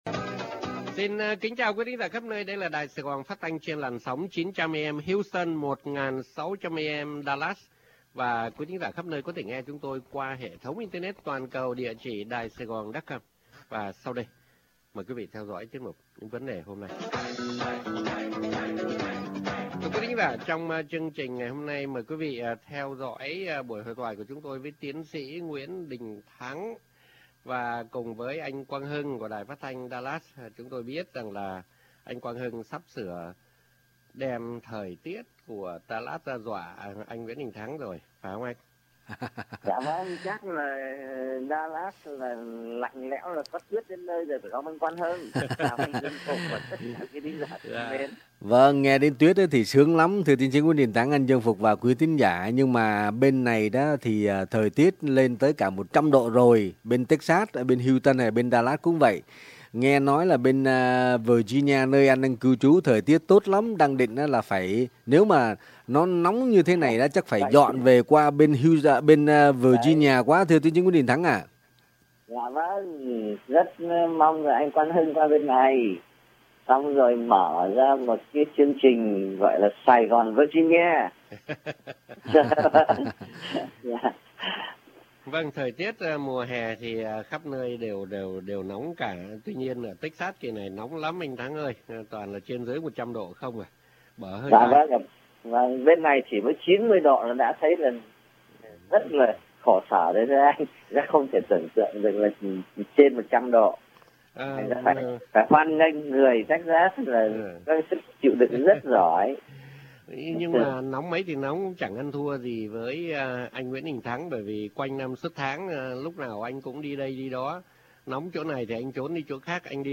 Phỏng Vấn & Hội Thoại | Radio Saigon Dallas - KBDT 1160 AM